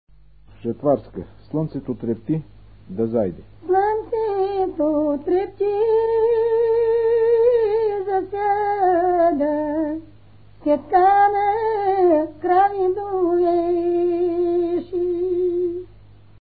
музикална класификация Песен
размер Безмензурна
фактура Едногласна
начин на изпълнение Солово изпълнение на песен
битова функция На жетва
начин на записване Магнетофонна лента